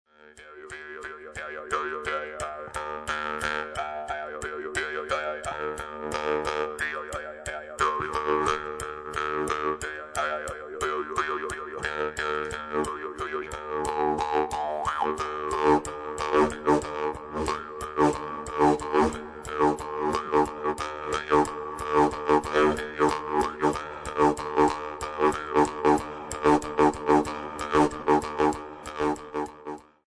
Playing a Jew's Harp
jewsharp25.mp3